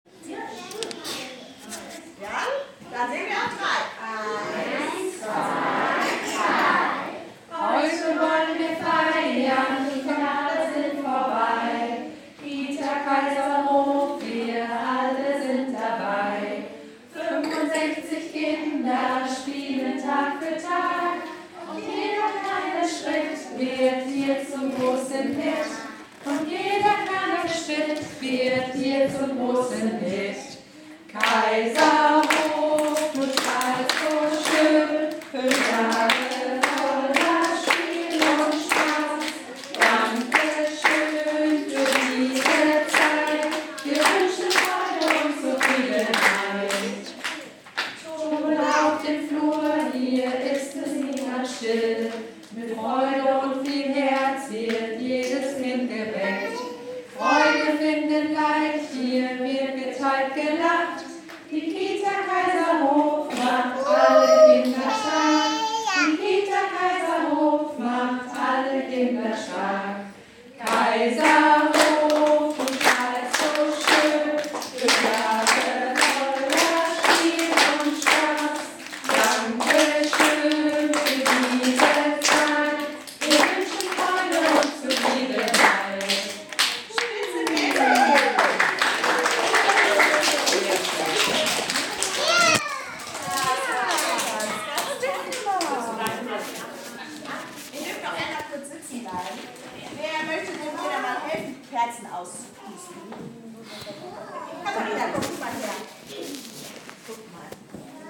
Dazu wurde der Flur festlich geschmückt und das persönliche Ständchen, welches alle Kinder mit strahlenden Gesichtern dem Kaiserhof gebracht haben, war ein absoluter Ohren- und Augenschmaus!
Um 9 Uhr haben sich alle Kinder und Erzieherinnen in der Turnhalle getroffen, um dem Kaiserhof gebürtig zu gratulieren.